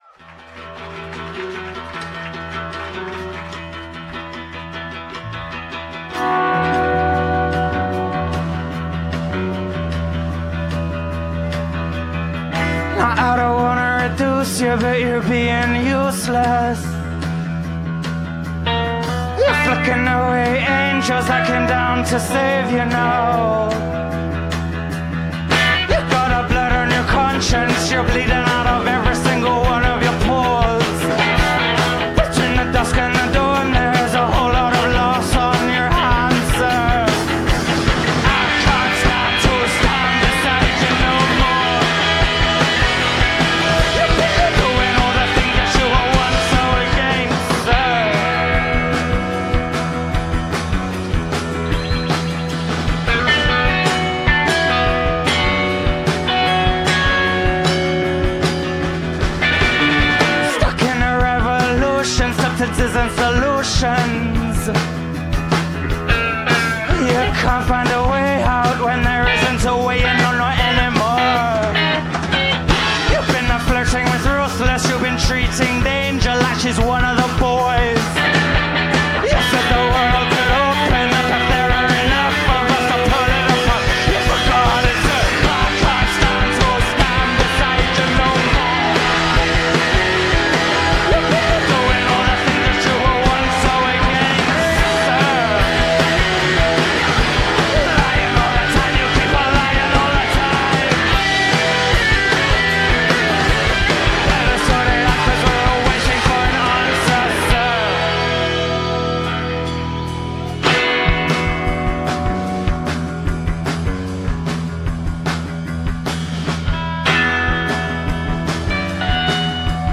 indie supergroup
guitar and drums